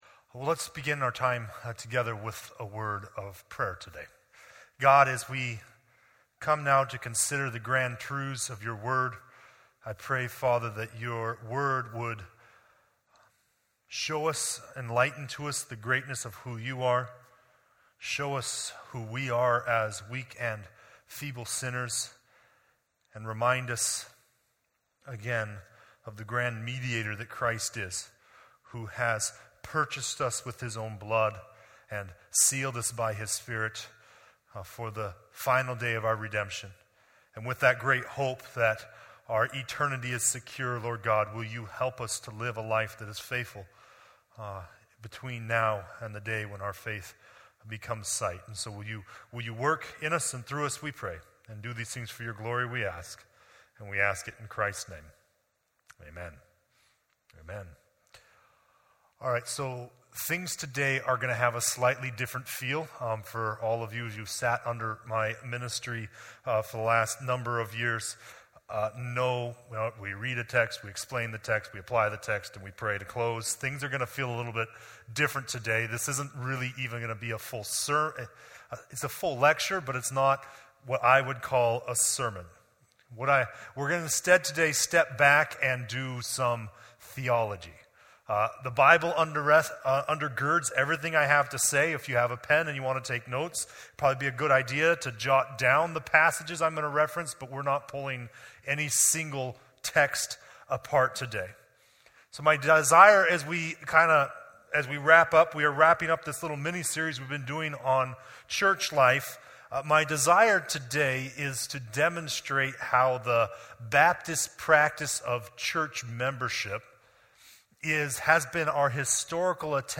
Sermons | Forest Baptist Church